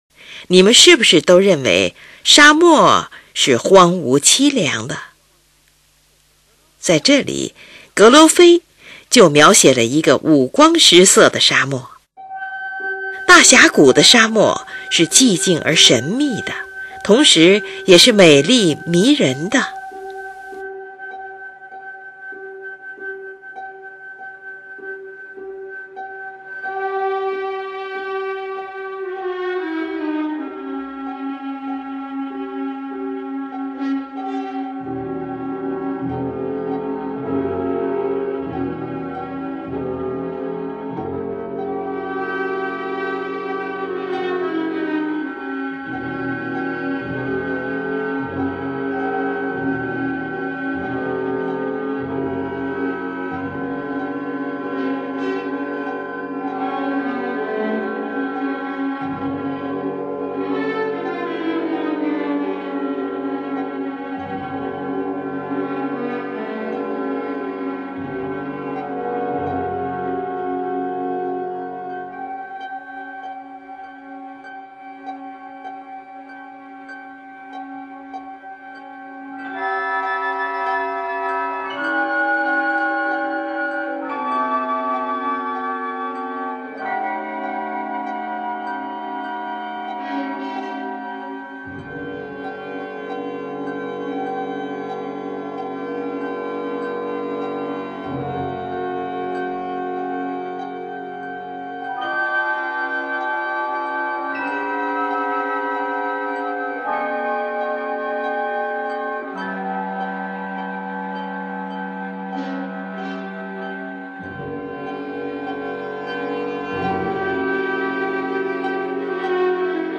同时，木管乐器和钢片琴不时以建立的不和谐和弦穿来，展现出沙漠的扑朔迷离海市蜃楼的奇观。